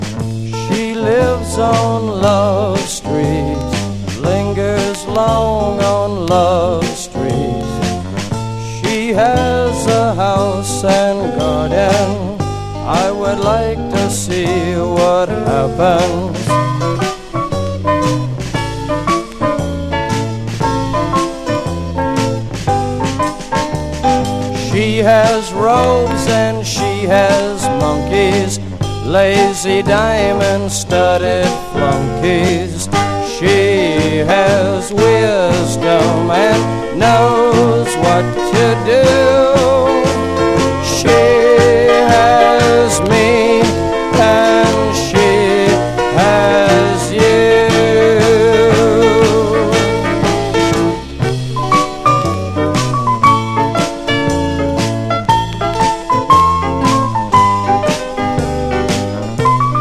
COUNTRY ROCK
スウィートなカントリー・ロック・クリスマス！